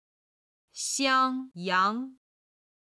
今日の振り返り！中国語発声